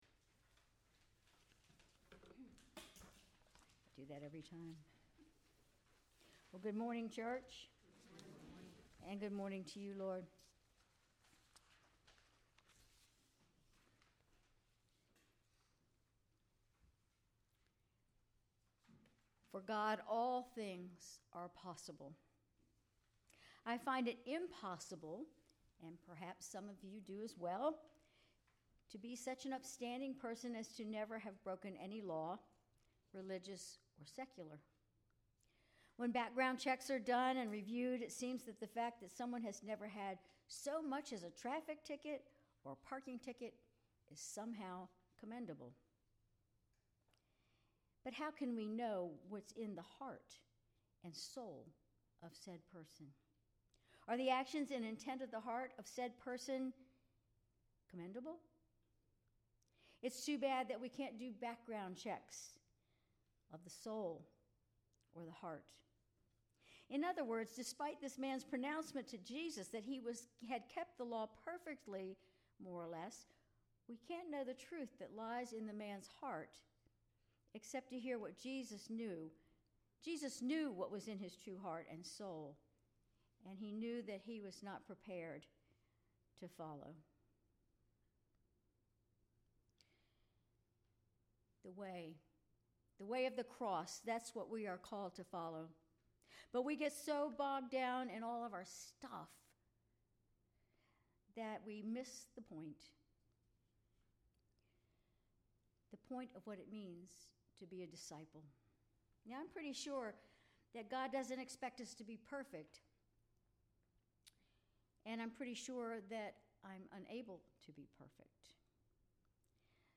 Sermon October 13, 2024